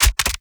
GUNMech_Rocket Launcher Reload_06_SFRMS_SCIWPNS.wav